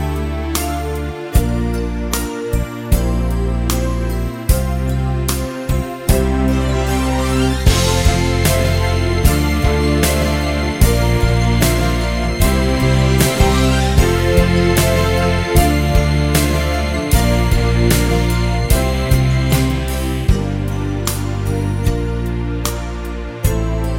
no Backing Vocals Irish 3:03 Buy £1.50